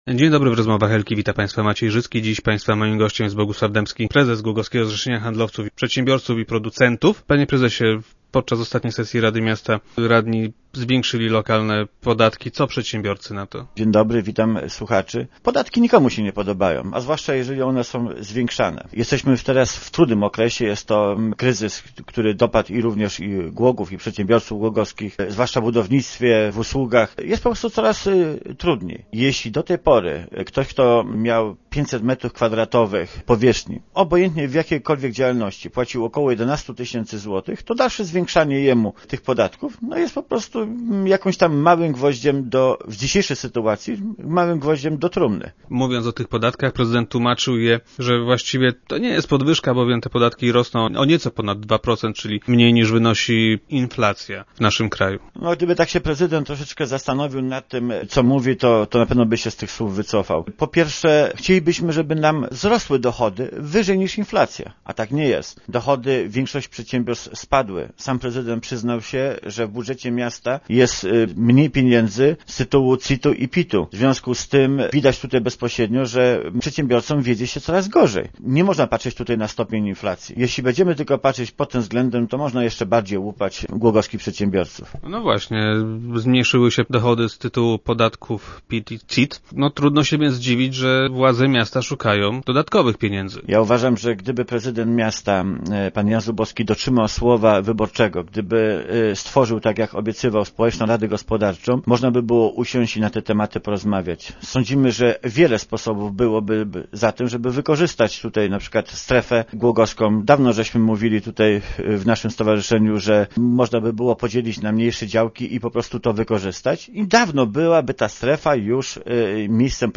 Jeżeli tylko na to będą patrzeć nasze władze, to jeszcze bardziej będzie można łupić lokalne firmy - mówił na antenie gość Rozmów Elki.